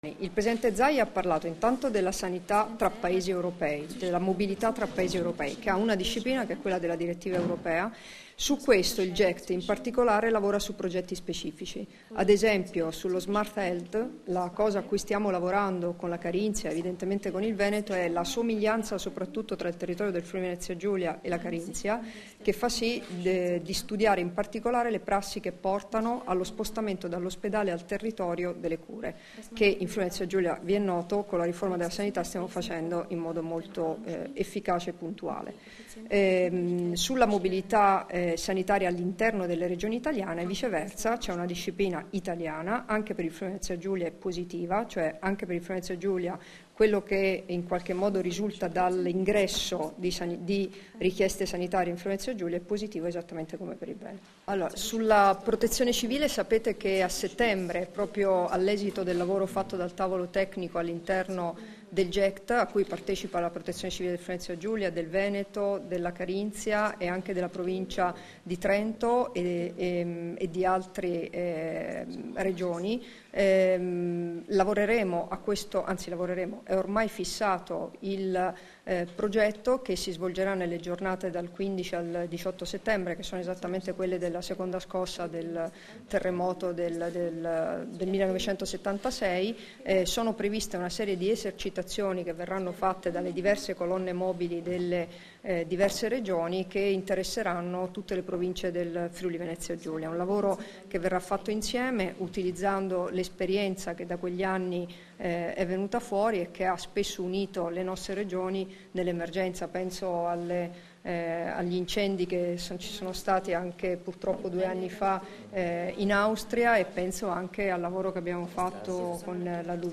Dichiarazioni di Debora Serracchiani (Formato MP3) [2070KB]
a margine dell'ottava assemblea del Gruppo Europeo di Cooperazione Territoriale (GECT) Euregio Senza Confini, rilasciate a Trieste il 23 maggio 2016